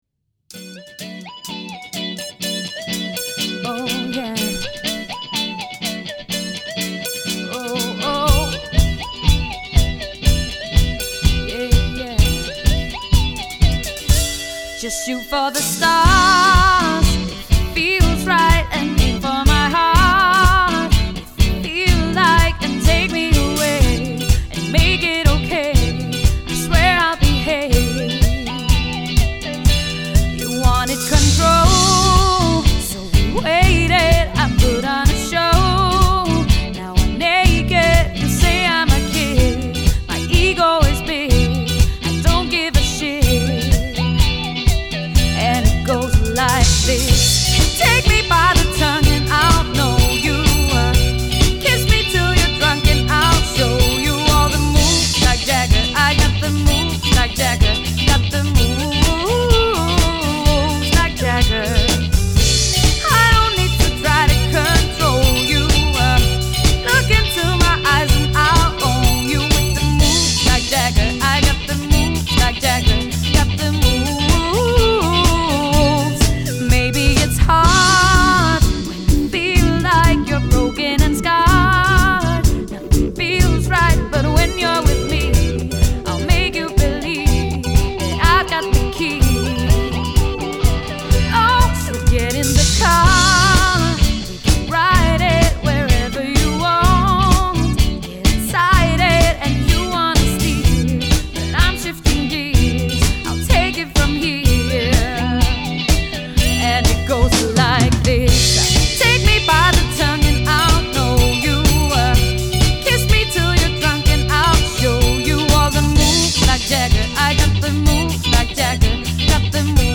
• Allround Partyband
• Coverband